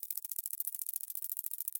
Звуки сколопендры - скачать и слушать онлайн бесплатно в mp3
Сколопендра карабкается по древесным корням